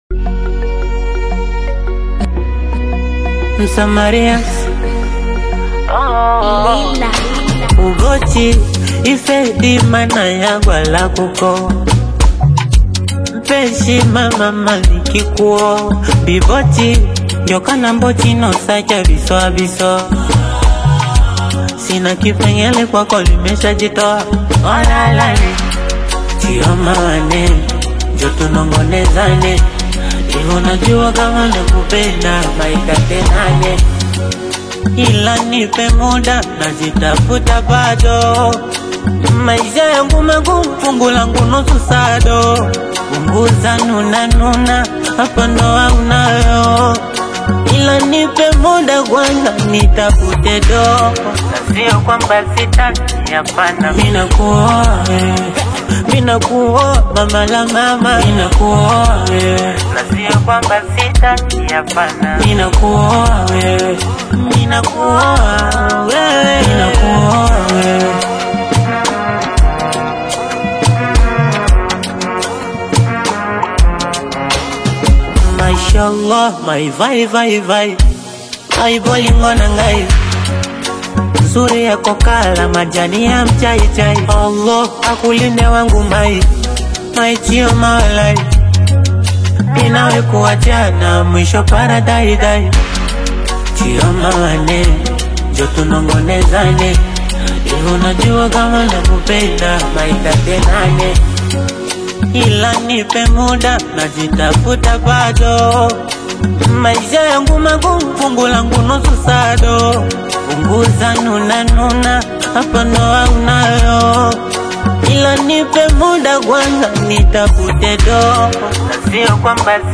romantic Afro-Beat/Afro-Pop love song